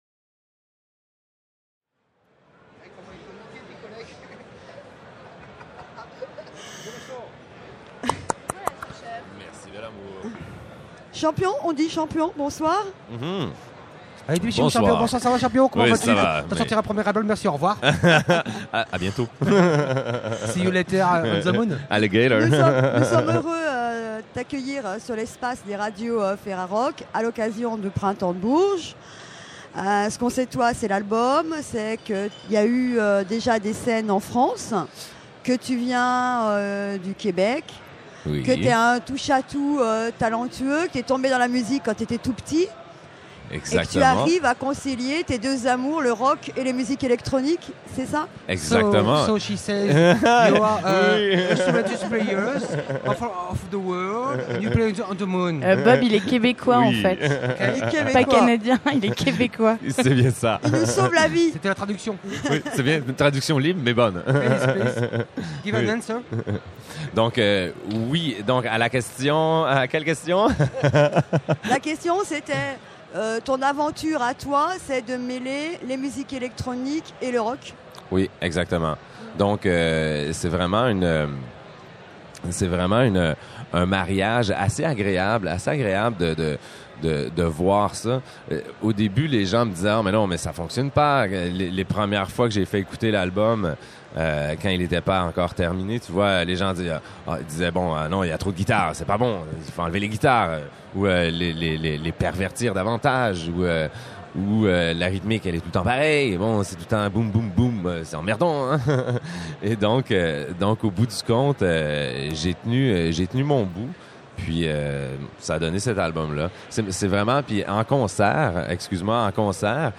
Champion Festival du Printemps de Bourges 2006 : 40 Interviews à écouter !